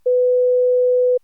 Here you can compare the 500 Hz tone with the ones below and guess wich one that is the octave.